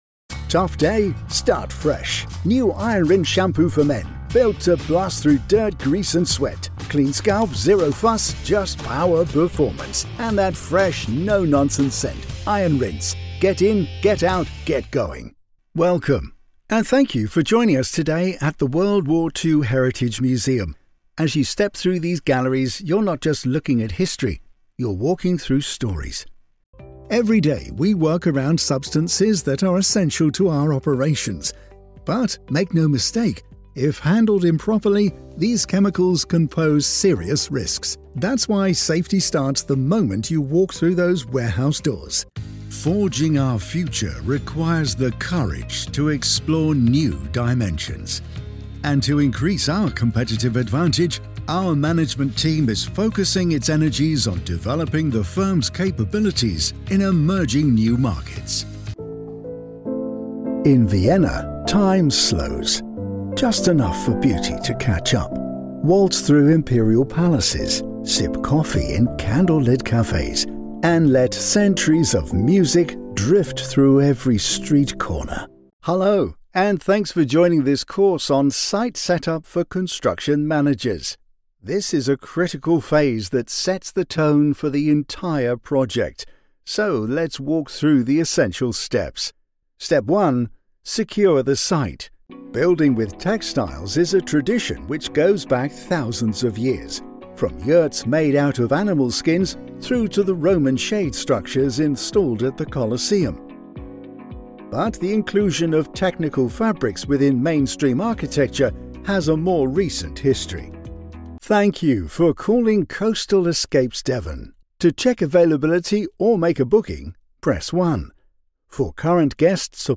Inglés (Británico)
Versátil, Seguro, Empresarial, Maduro, Natural
Comercial